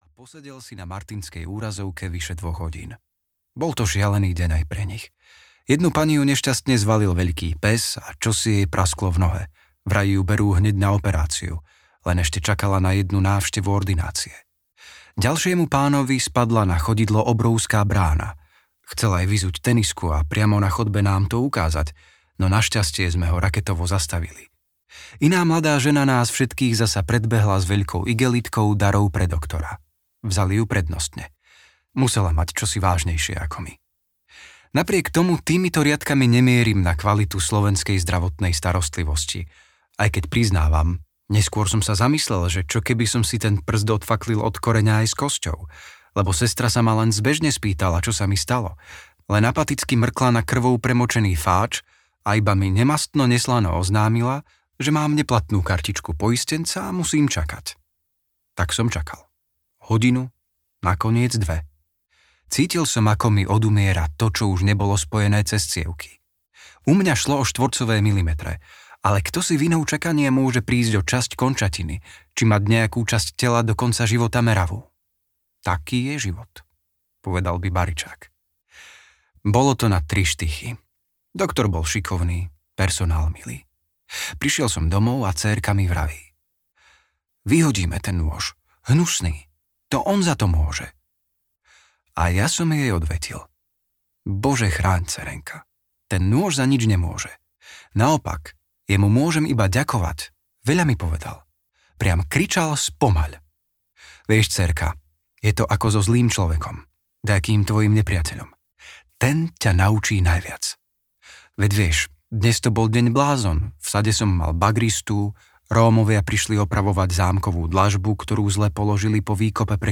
Vzťahy audiokniha
Ukázka z knihy
vztahy-audiokniha